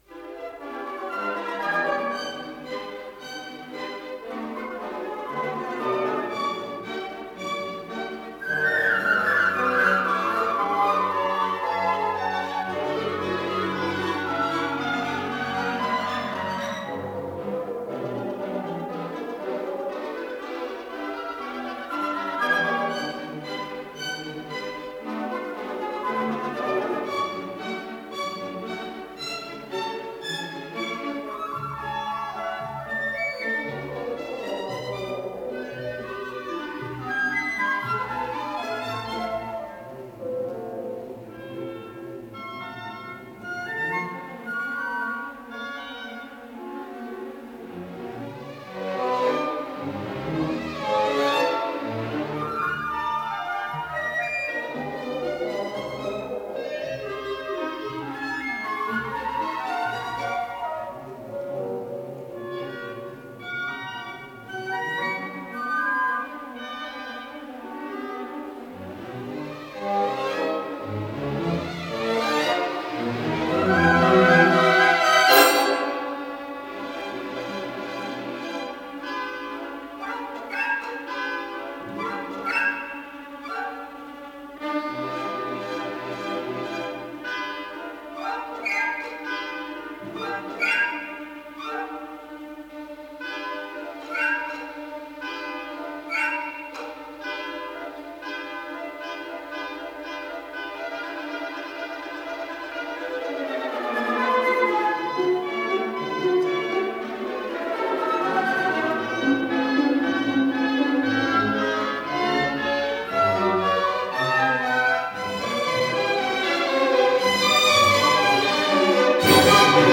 ПодзаголовокВ 4-х картинах по драматической поэме Байрона, си минор
Виваче кон спирито
ИсполнителиГосударственный симфонический оркестр СССР
Художественный руководитель и дирижёр - Евгений Светланов
Скорость ленты38 см/с
ВариантДубль моно